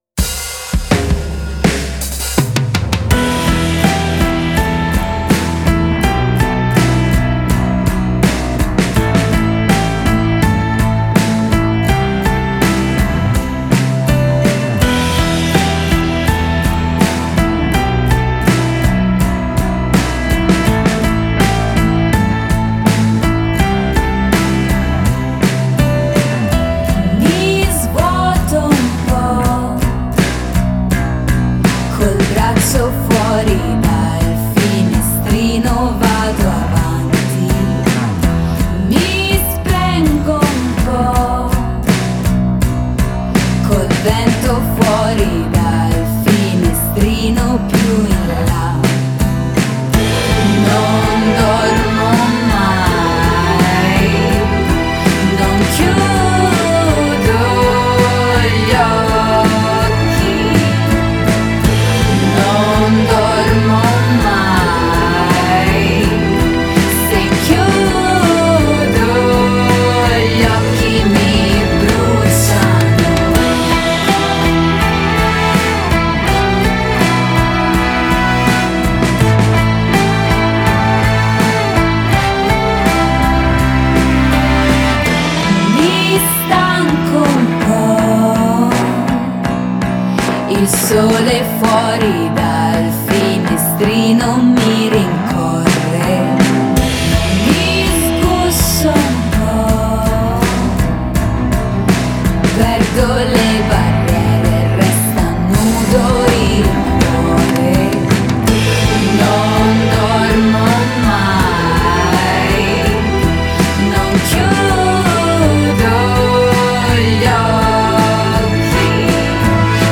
Genre: Pop Rock, Indie